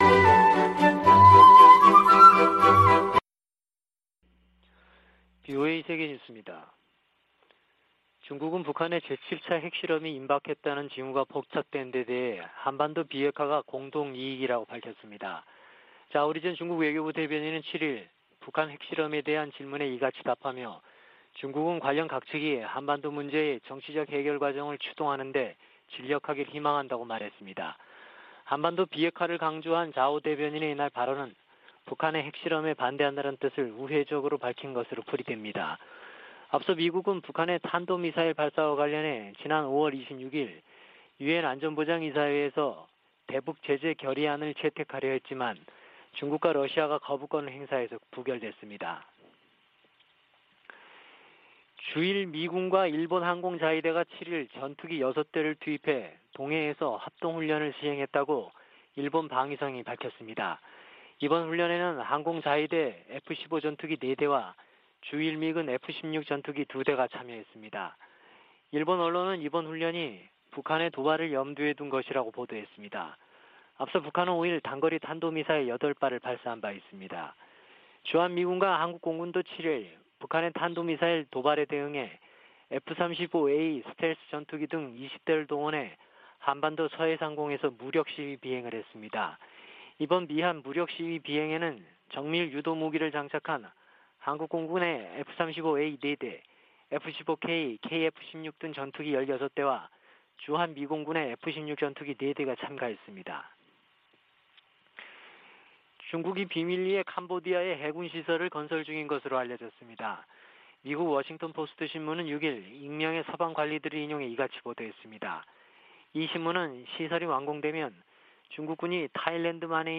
VOA 한국어 아침 뉴스 프로그램 '워싱턴 뉴스 광장' 2022년 6월 8일 방송입니다. 북한이 7차 핵실험을 감행할 경우 미국과 한국은 신속하고 강력한 대응을 할 것이라고 서울을 방문중인 웬디 셔먼 미국 국무부 부장관이 경고했습니다. 국제원자력기구는 북한 풍계리에서 핵실험을 준비 징후를 포착했다고 밝혔습니다. 미국의 전문가들은 북한이 최근 8발의 단거리탄도미사일을 발사한 것은 전시 한국에 기습 역량을 과시하려는 것이라고 분석했습니다.